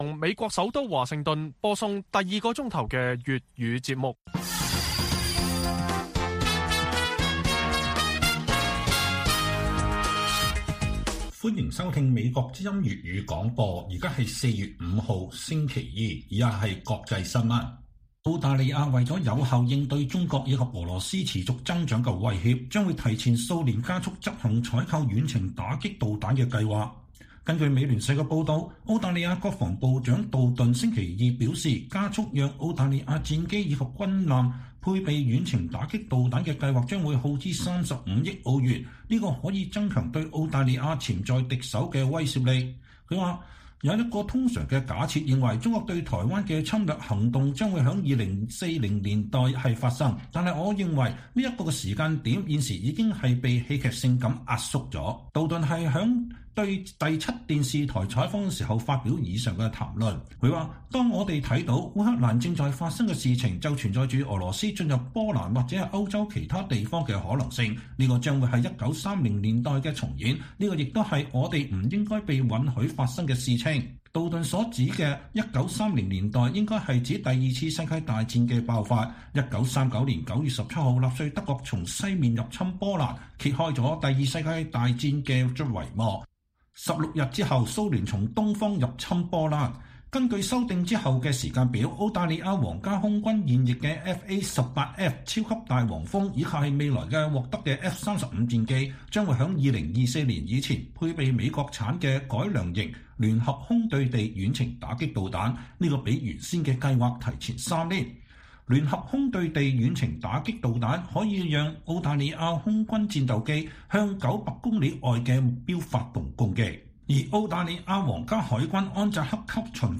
粵語新聞 晚上10-11點: 面對持續增長的中俄威脅，澳大利亞加速採購遠程打擊導彈